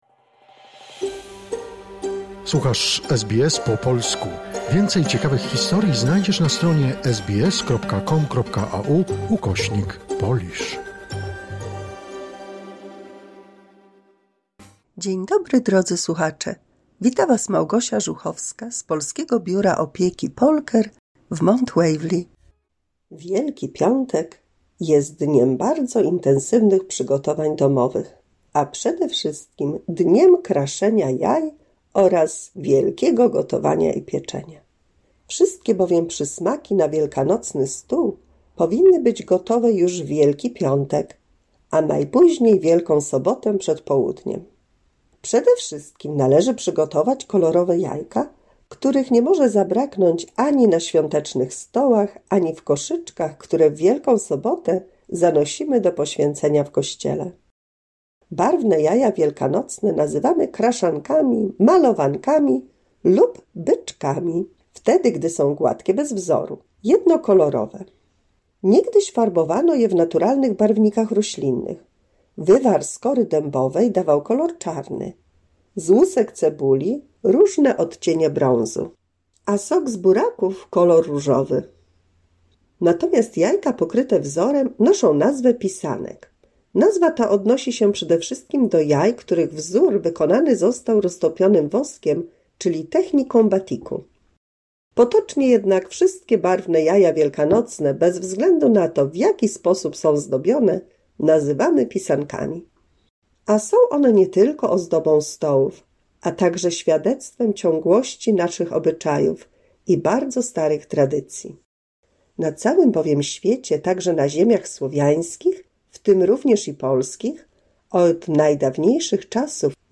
199 słuchowisko dla polskich seniorów, a w nim dzisiaj wszystko o Świętach Wielkanocnych i polskich, wielkanocnych tradycjach.